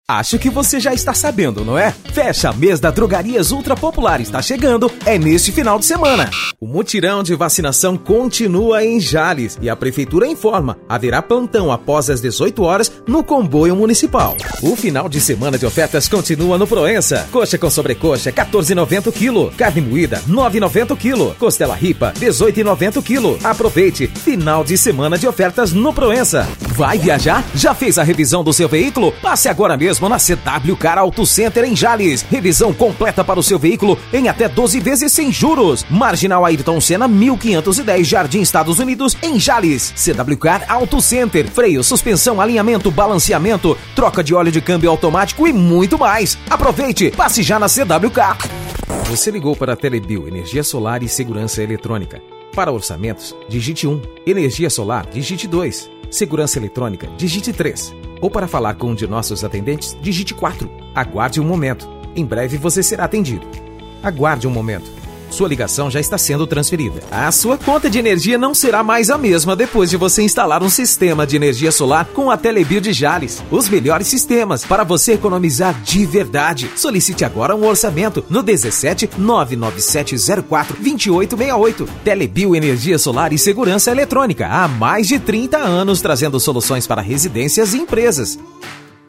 Animada
Caricata